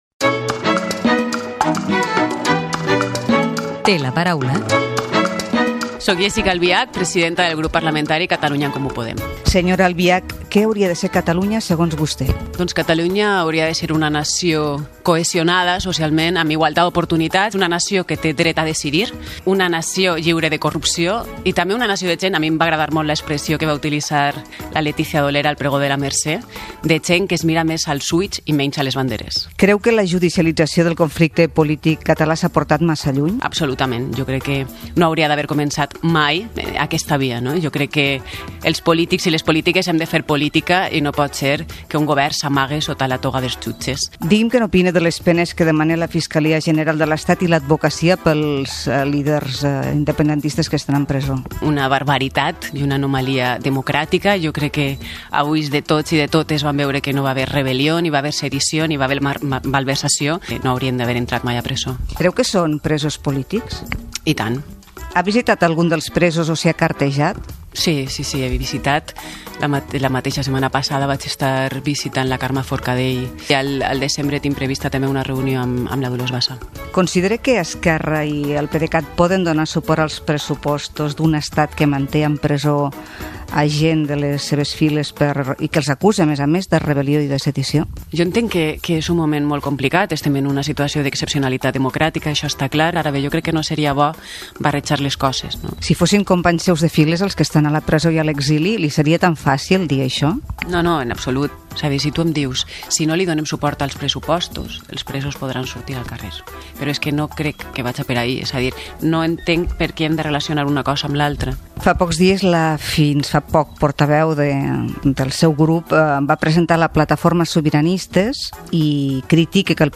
Entrevista a la política Jéssica Albiach presidenta de Catalunya en Comú Podem al Parlament de Catalunya. Expressa la seva opinió sobre l'empresonament dels polítics implicats en la convocatòria del Referèndum d'Autodeterminació de Catalunya de l'1 d'octubre i la legislatura vigent
Informatiu